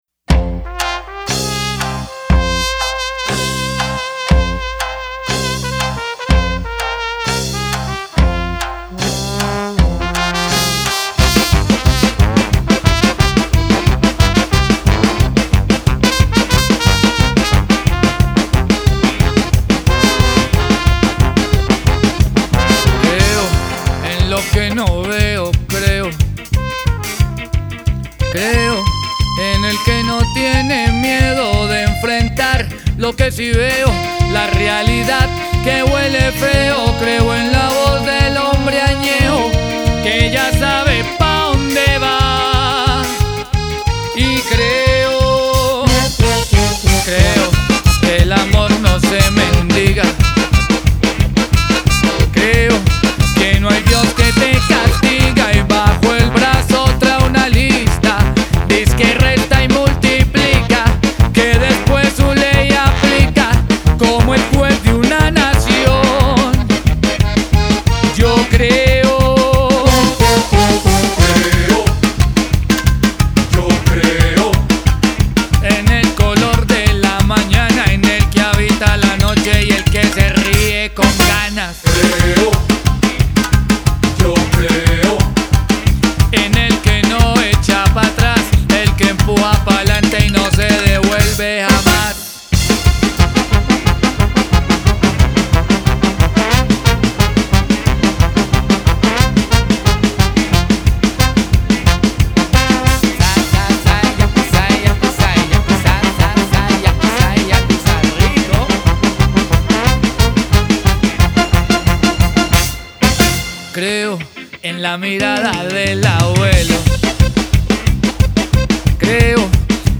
Rock latino